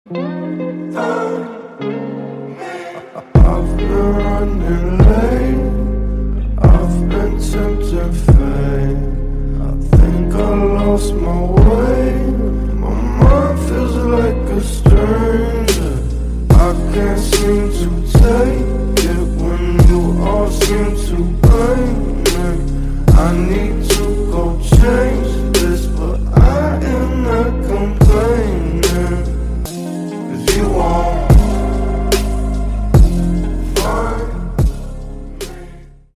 Спокойные И Тихие Рингтоны
Танцевальные Рингтоны